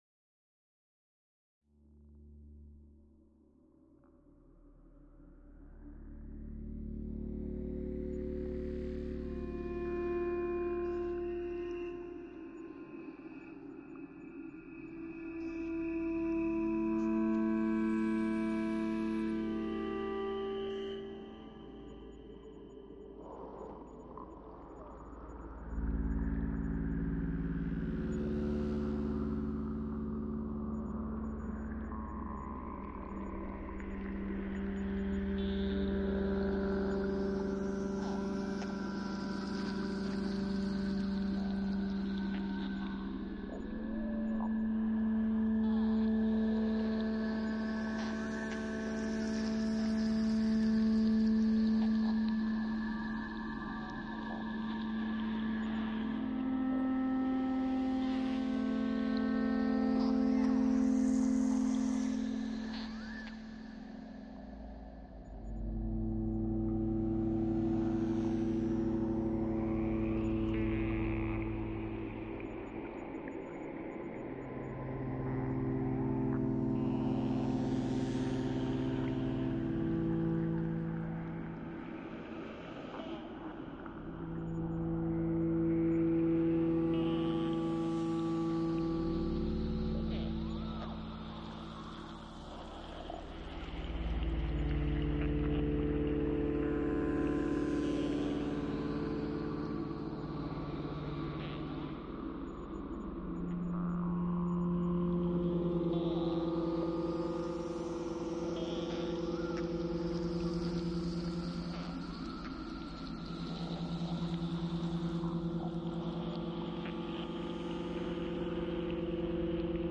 描述：来自各种现场录音的缓慢，旋律，背景音景。
Tag: 声景 AMB ience 背景 无人机 不断发展 大气 旋律